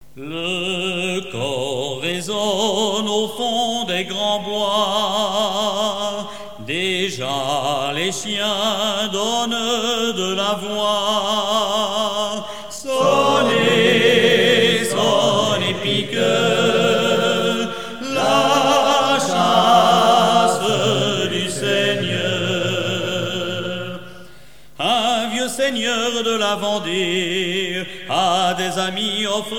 rencontre de sonneurs de trompe
Pièce musicale éditée